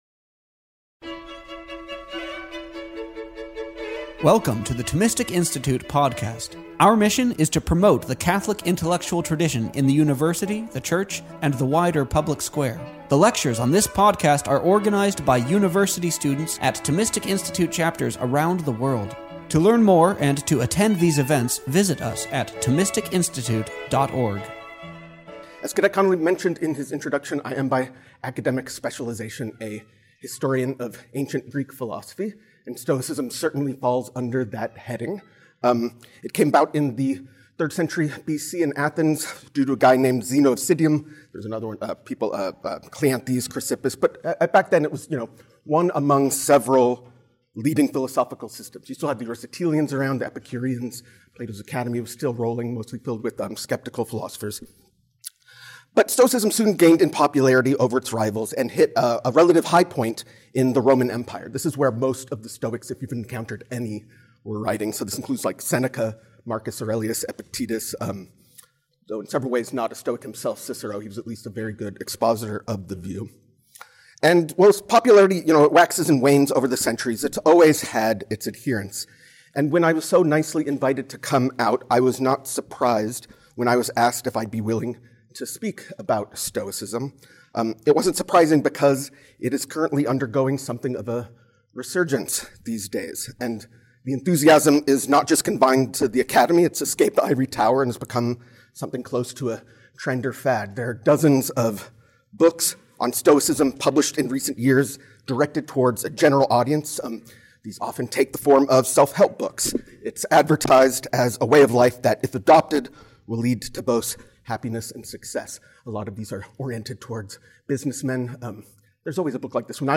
This talk was given as part of the Thomistic Institute's Holy Week Retreat, April 9-12, 2020.